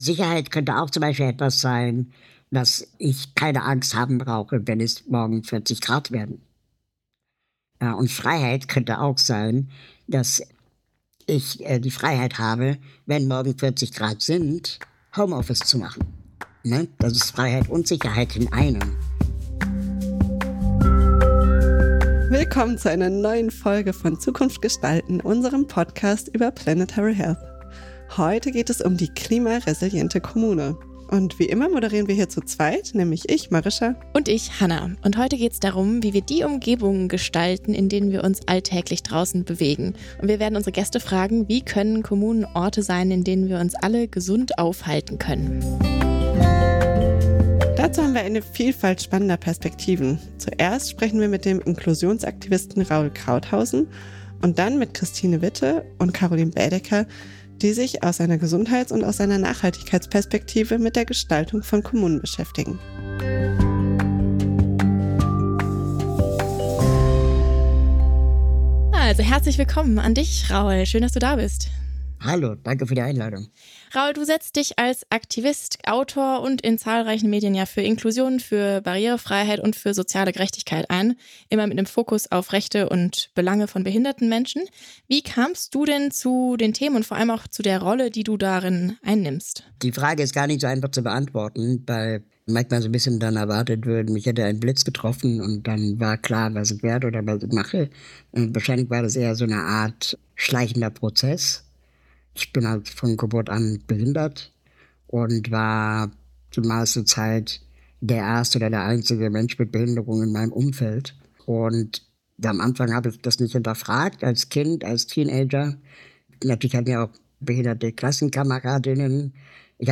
Im Mittelpunkt steht die Frage, wie klimaresiliente Kommunen entstehen können – und warum sie entscheidend für Gesundheit, Teilhabe und Lebensqualität sind. Zu Beginn spricht Inklusionsaktivist Raúl Krauthausen über Barrierefreiheit und soziale Gerechtigkeit.